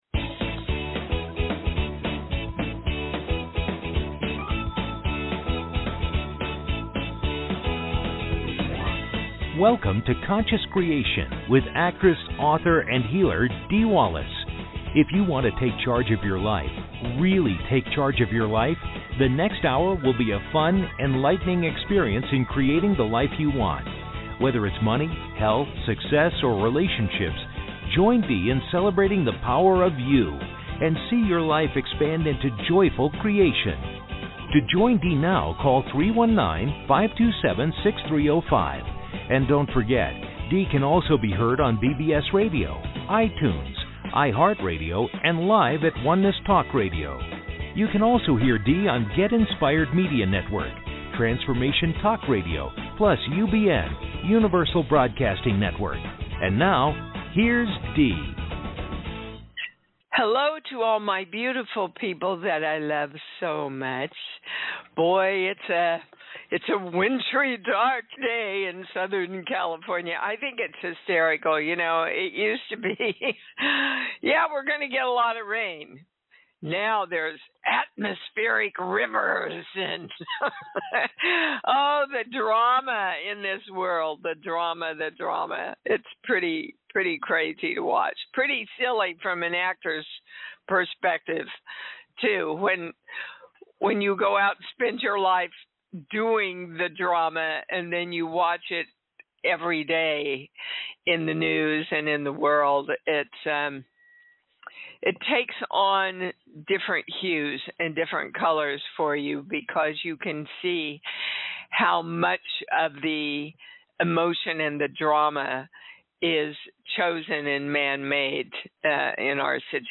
Talk Show Episode, Audio Podcast, Conscious Creation and with Dee Wallace on , show guests , about Dee Wallace,conscious creation,I am Dee Wallace, categorized as Kids & Family,Philosophy,Psychology,Self Help,Society and Culture,Spiritual,Access Consciousness,Medium & Channeling,Psychic & Intuitive